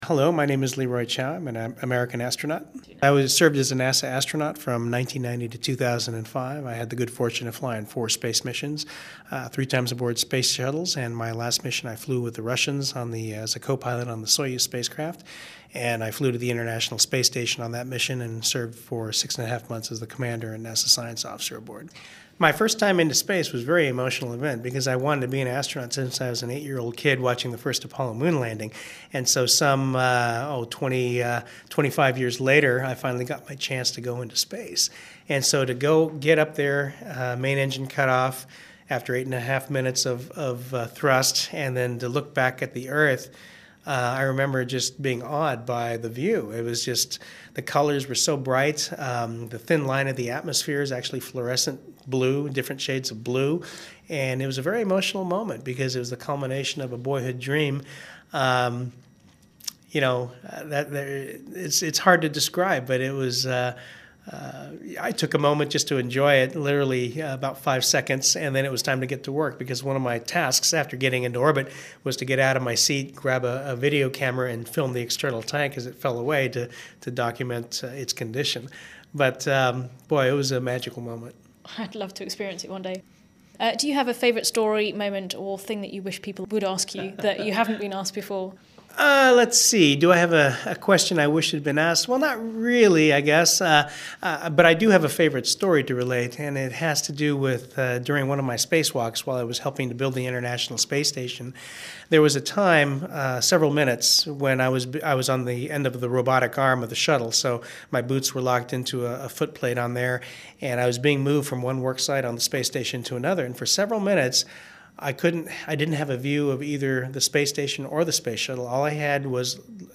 Not only did Leroy take the time to have lunch with me, he also spared the time to do an interview and promised to put me in touch with some astronauts who might be in Houston when I was there.